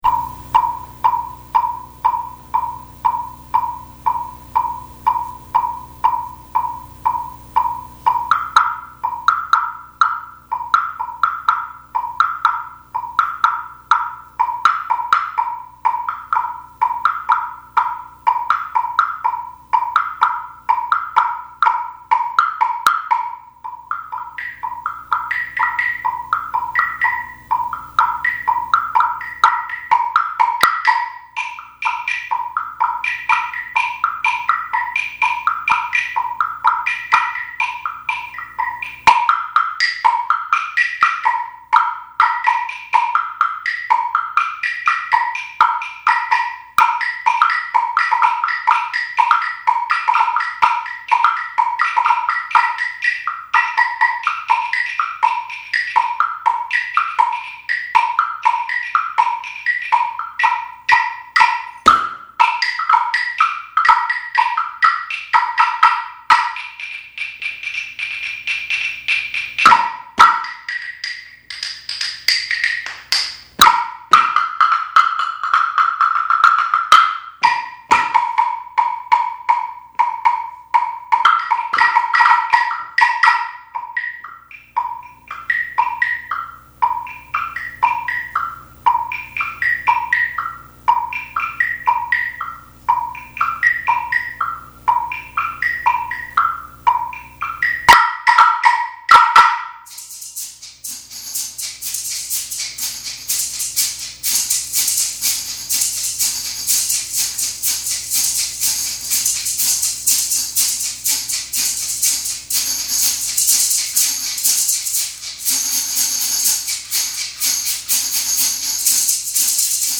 Genre: Percussion Ensemble
# of Players: 9
Percussion 1 (high claves)
Percussion 3 (woodblock)
Percussion 4 (temple block)
Percussion 5 (tambourine)
Percussion 8 (shekere)
Percussion 9 (drum set)